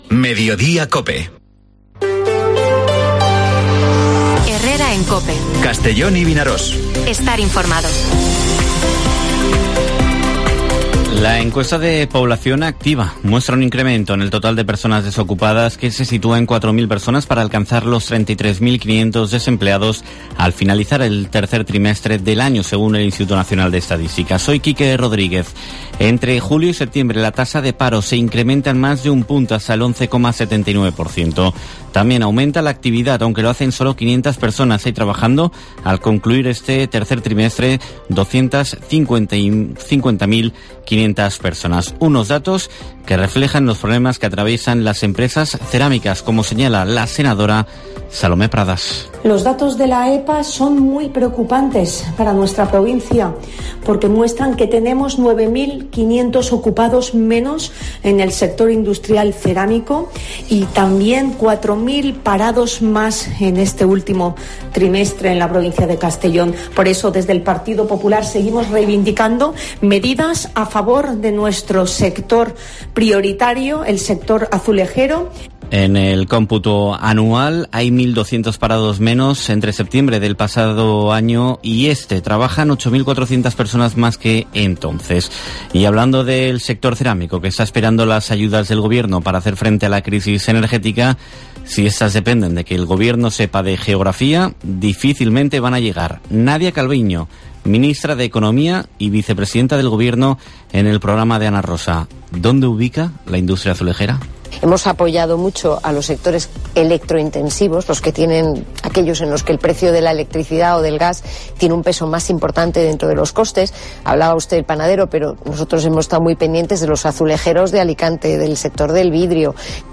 Informativo Mediodía COPE en la provincia de Castellón (27/10/2022)